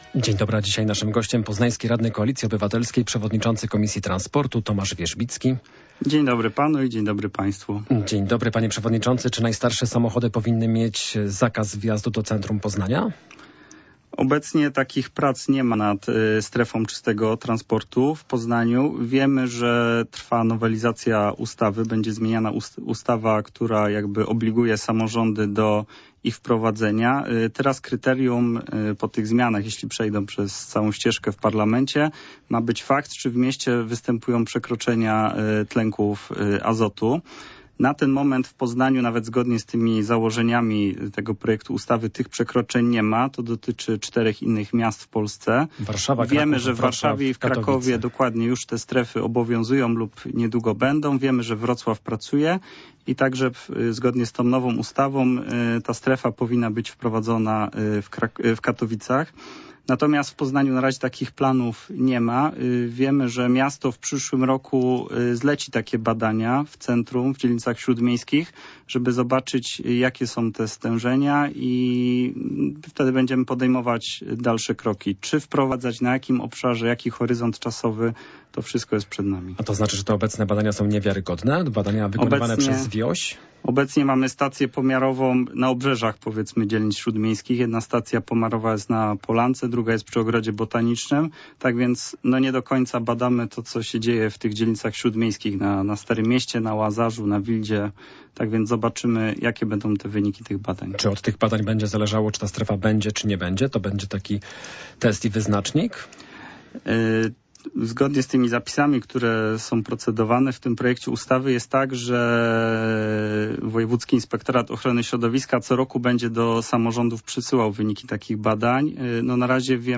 Popołudniowa rozmowa Radia Poznań - Tomasz Wierzbicki
Czy Poznań będzie miał strefę czystego transportu? Goście Radia Poznań jest radny Tomasz Wierzbicki.